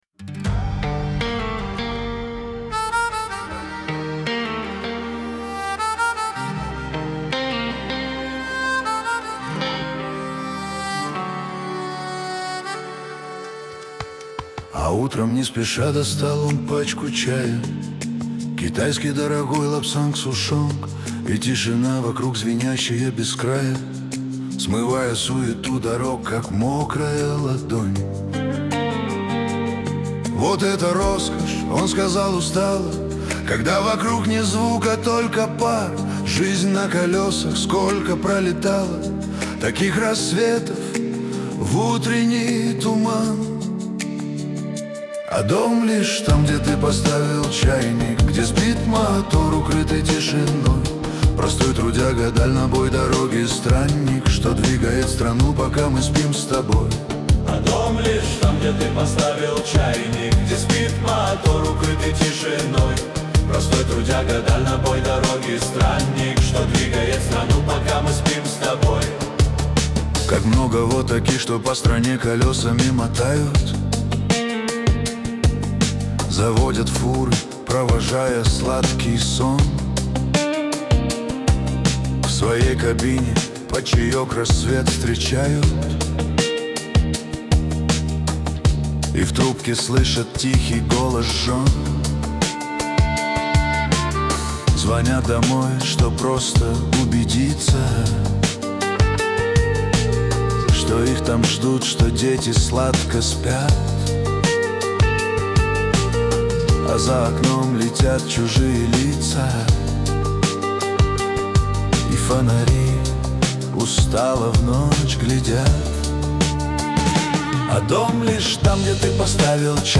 Лирика
Шансон